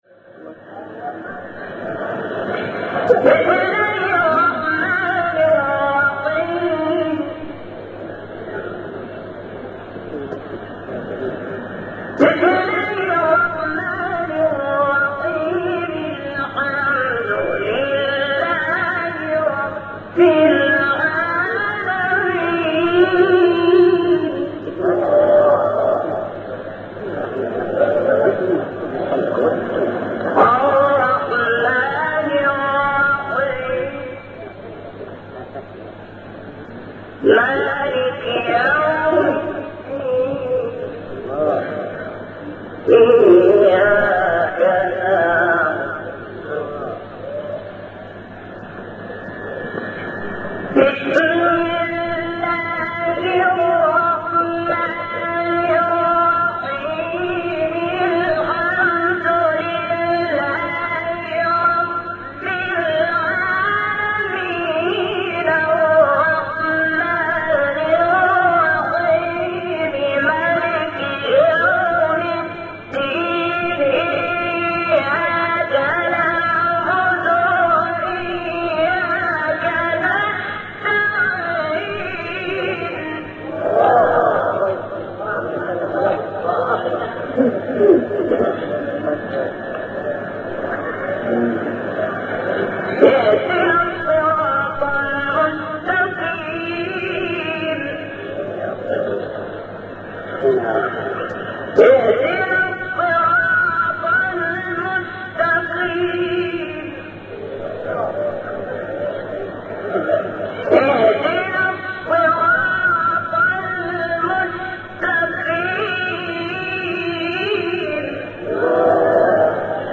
تلاوت سوره حمد و آیات ابتدایی سوره بقره شحات | نغمات قرآن | دانلود تلاوت قرآن